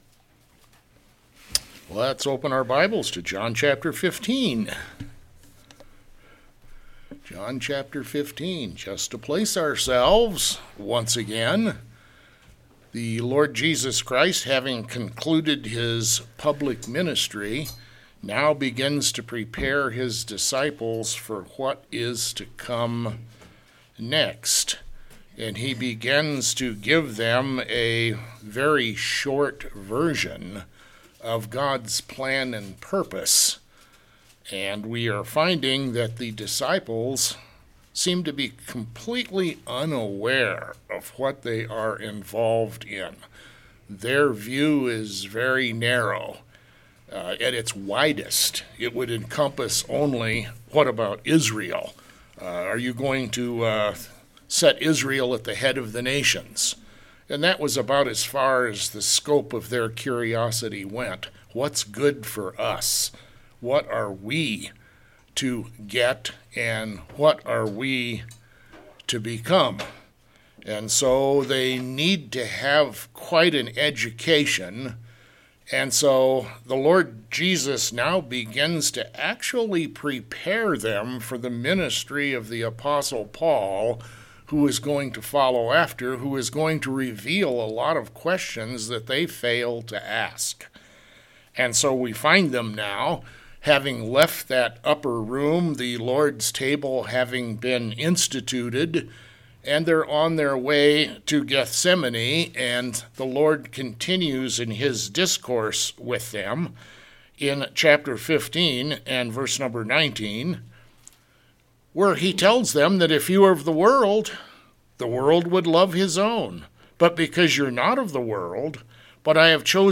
A Non-Denominational Bible Church in Black Forest, Colorado
Sermons